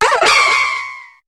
Cri de Viridium dans Pokémon HOME.